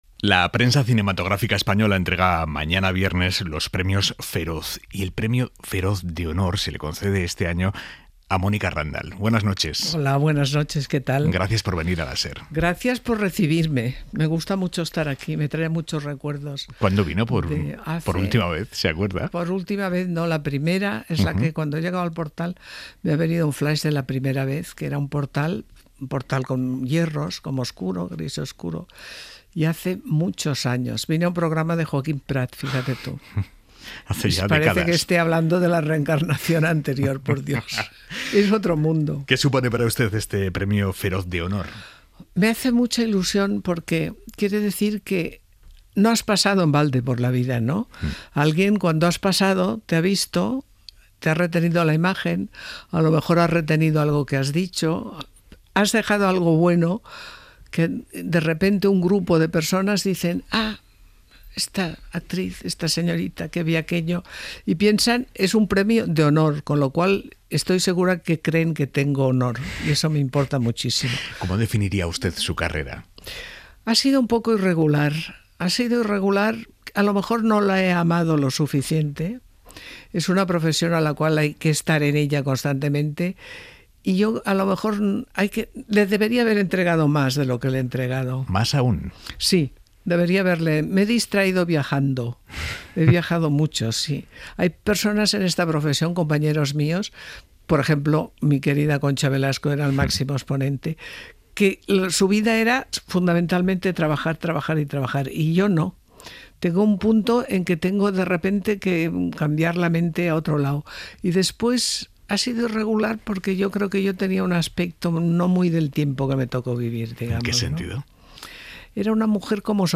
Aimar Bretos entrevista a la actriz Mónica Randall.